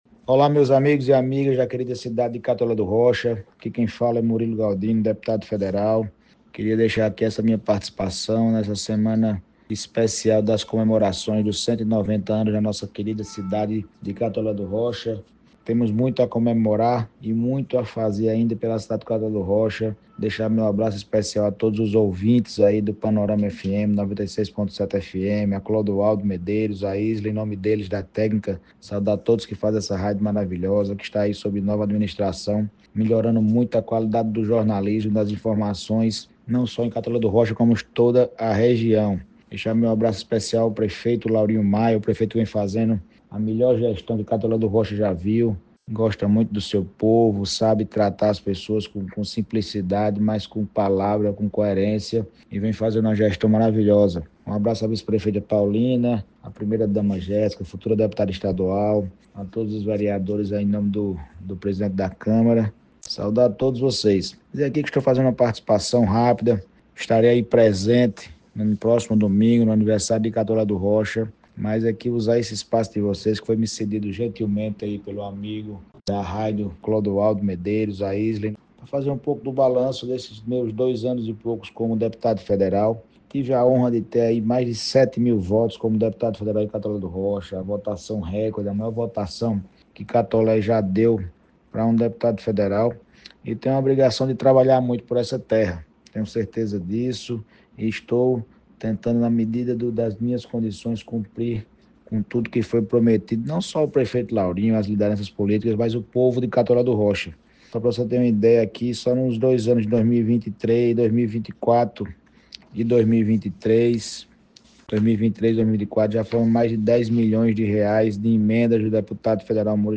A reportagem do Jornal Panorama Notícias entrou em contato com o deputado federal Murilo Galdino.
Vamos ouvir o Deputado Federal Murilo Galdino:
07-Deputado-Federal-Murilo-Galdino.mp3